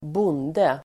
Uttal: [²b'on:de]